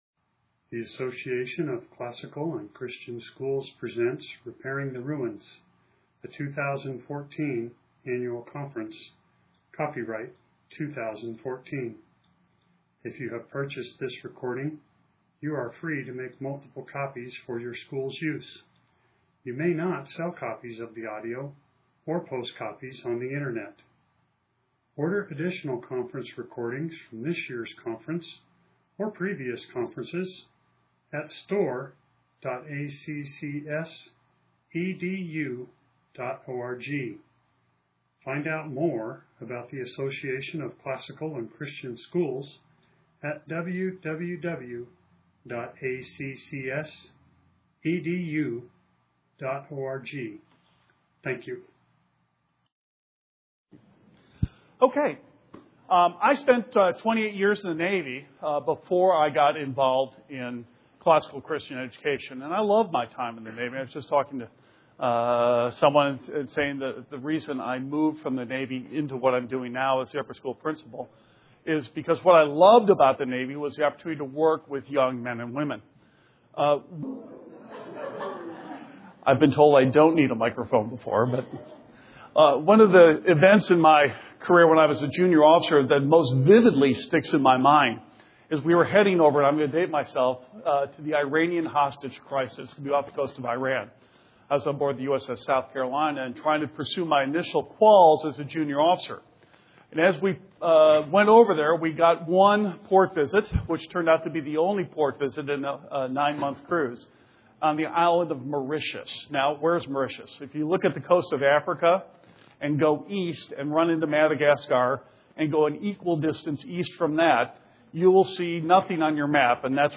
2014 Leaders Day Talk | 0:48:17 | Leadership & Strategic